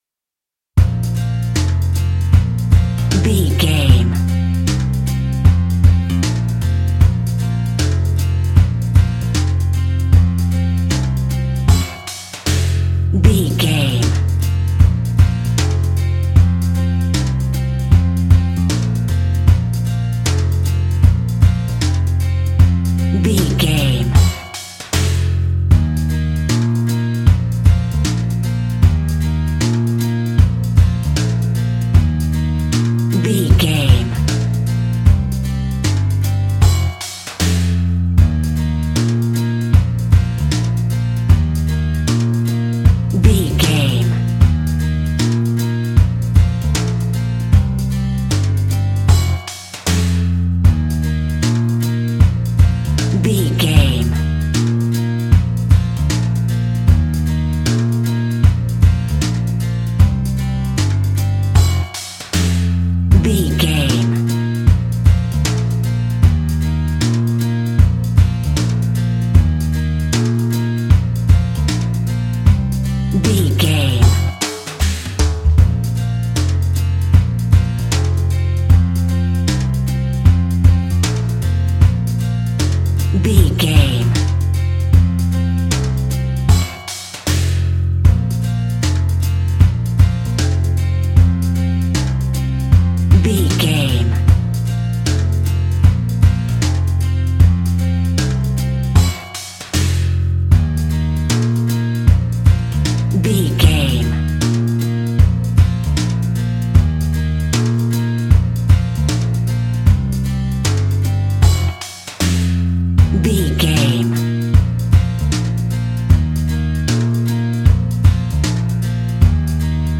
Ionian/Major
B♭
childrens music
instrumentals
fun
childlike
happy
kids piano